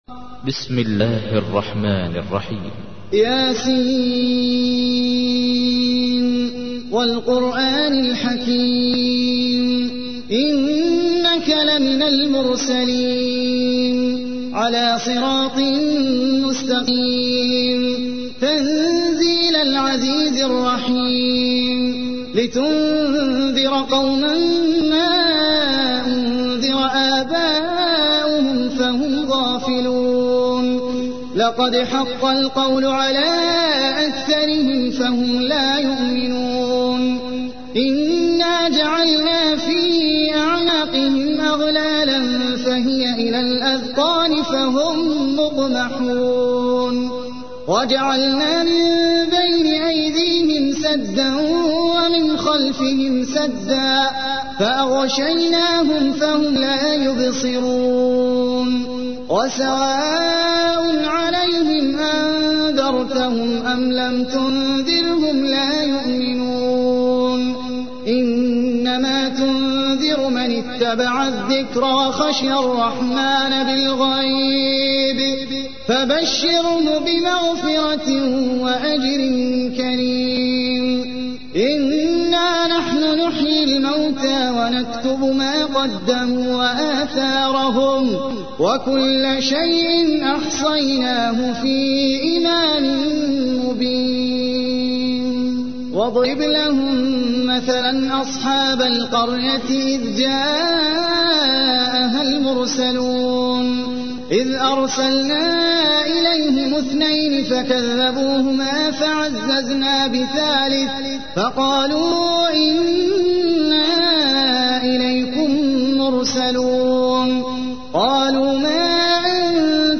تحميل : 36. سورة يس / القارئ احمد العجمي / القرآن الكريم / موقع يا حسين